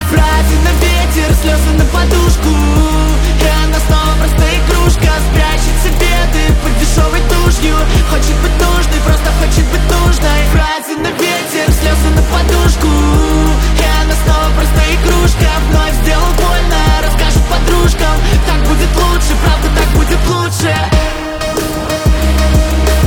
поп
грустные